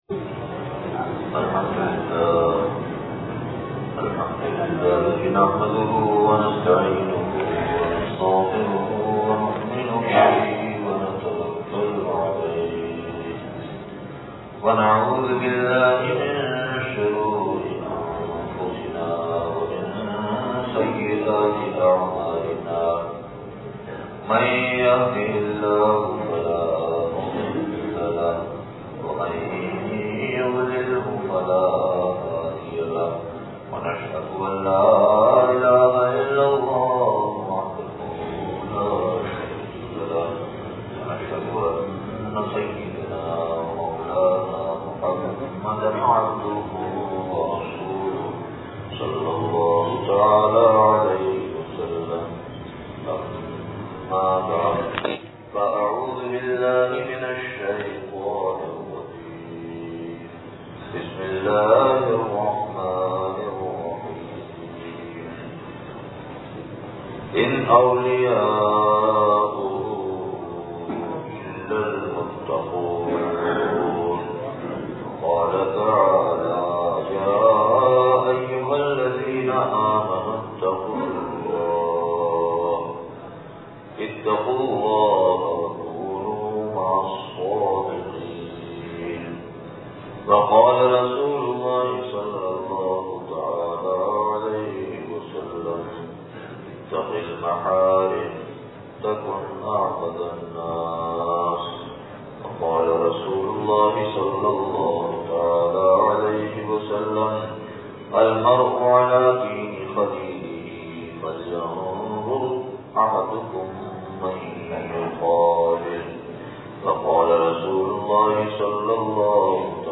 ریلوے اسٹیشن والی مسجد نواب شاہ سندھ (بعد عشاء بیان)